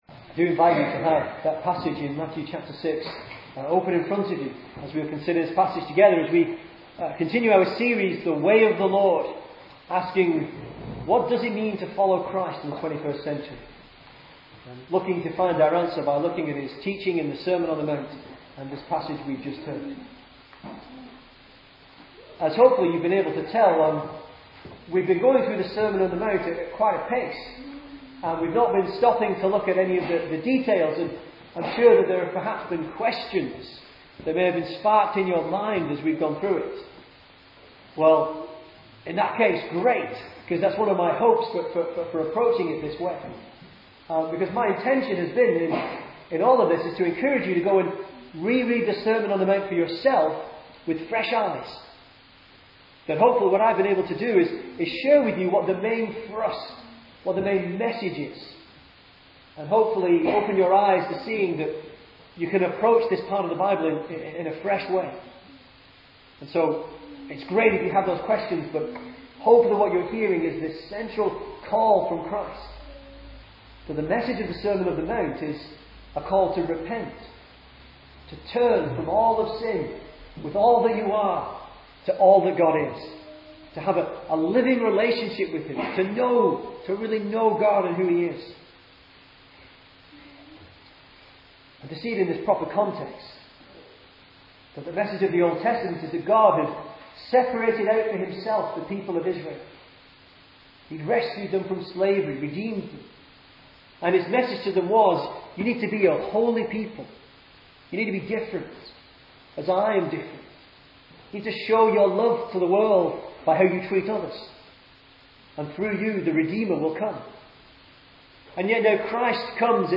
2010 Service Type: Sunday Morning Speaker